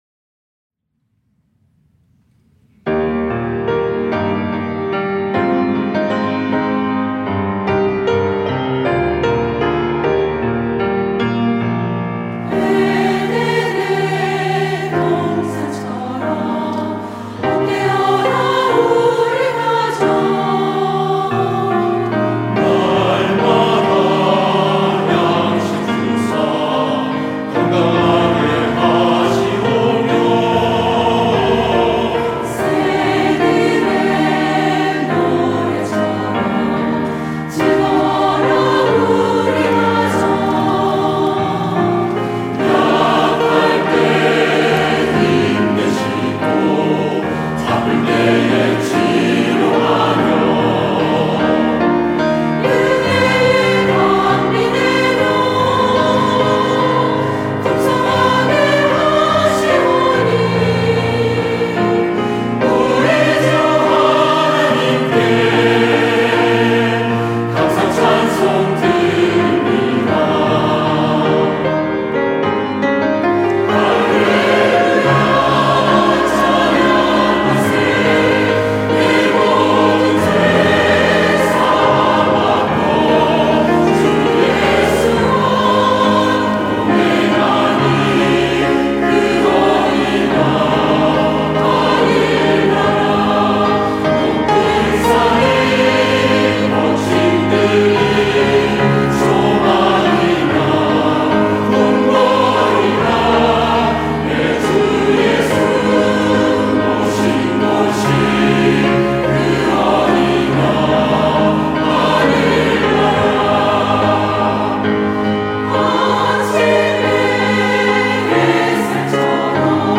할렐루야(주일2부) - 에덴의 동산에서
찬양대